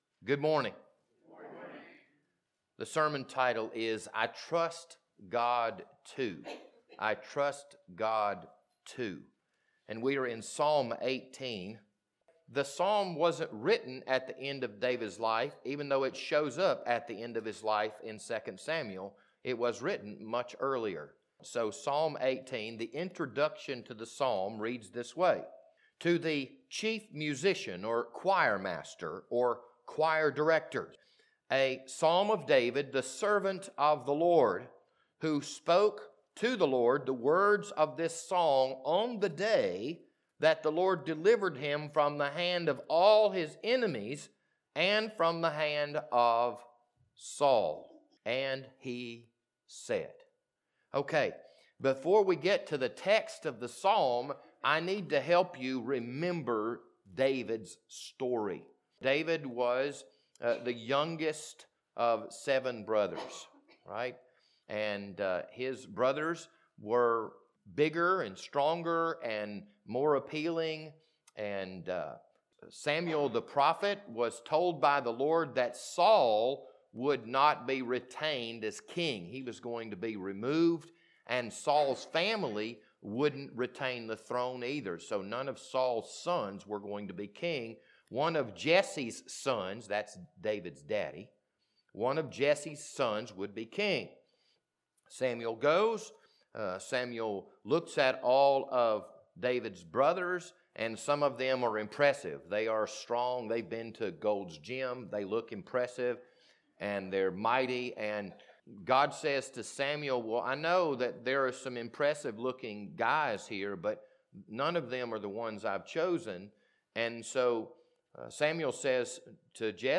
This Sunday morning sermon was recorded on March 27th, 2022.